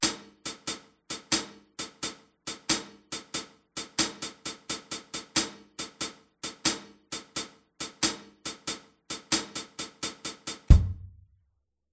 Bei allen triolischen Spielweisen wird häufig die zweite blaue Silbe ne beim Spielen ( NICHT ABER BEIM ZÄHLEN ) weggelassen.
Groove01-shuffle12.mp3